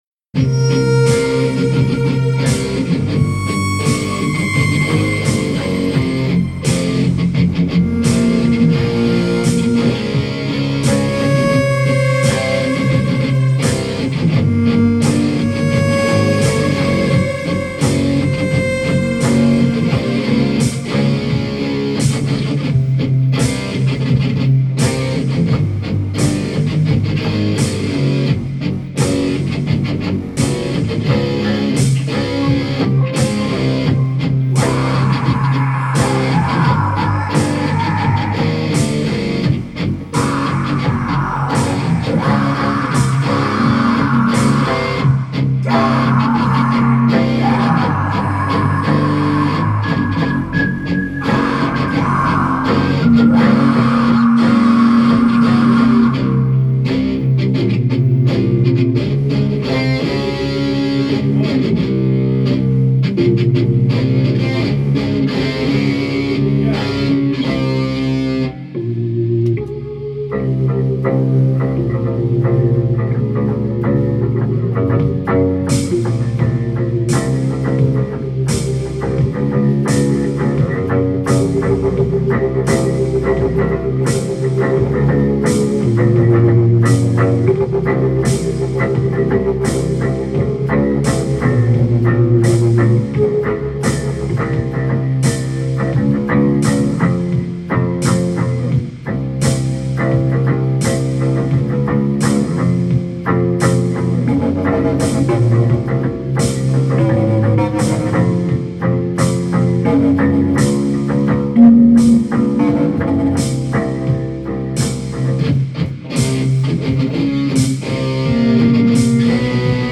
where: Marsonic
Jam